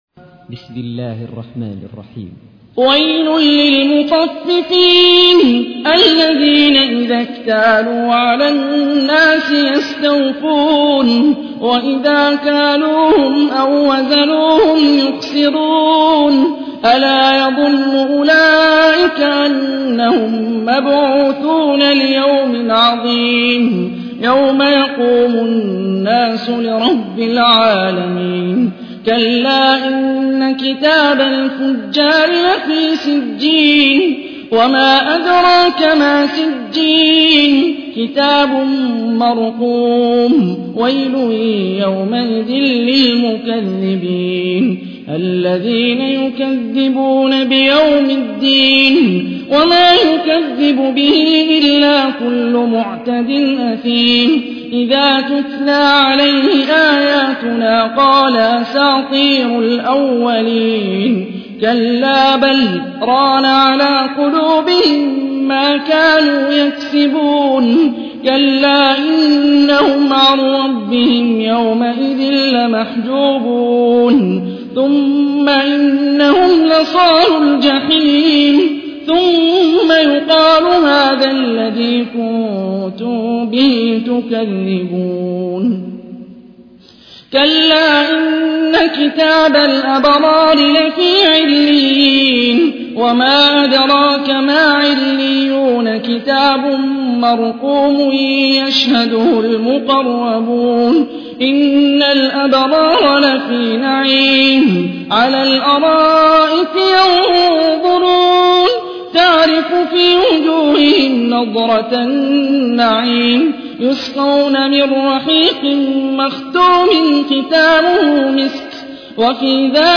تحميل : 83. سورة المطففين / القارئ هاني الرفاعي / القرآن الكريم / موقع يا حسين